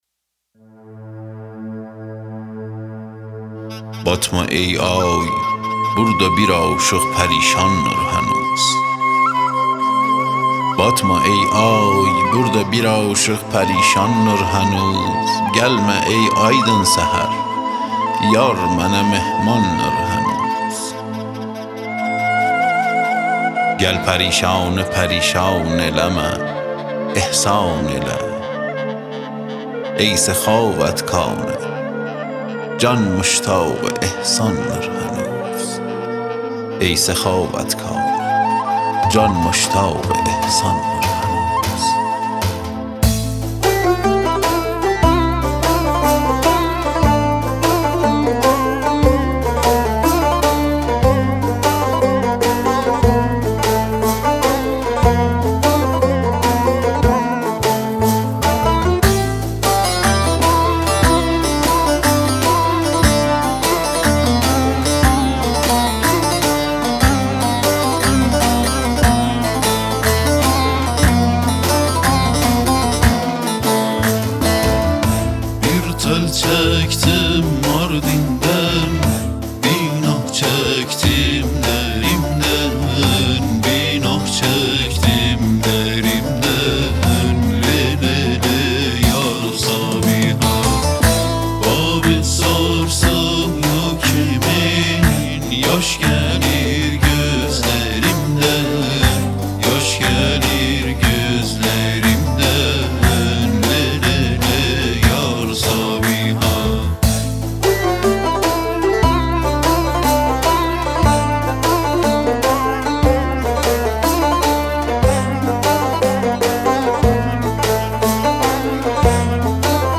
براساس ملودی تورکی اناتولی